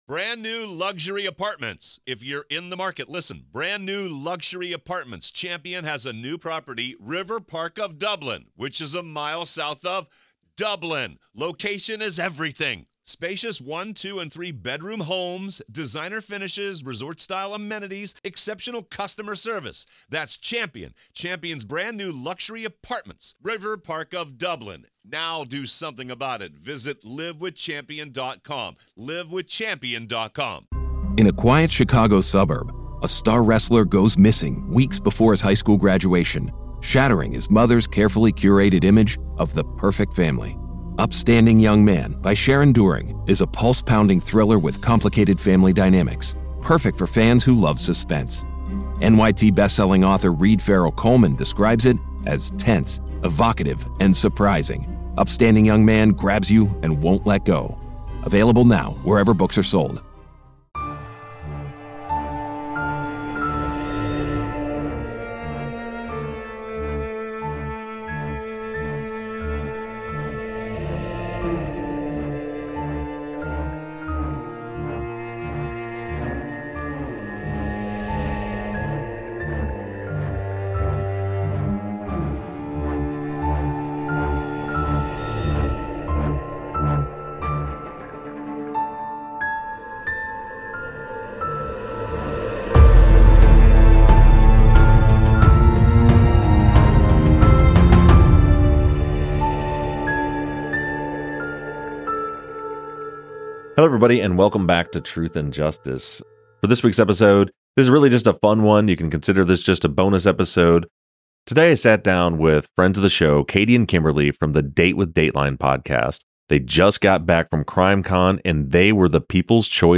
This is just a fun conversation between friends.